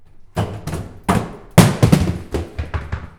tumble-down-stairs.wav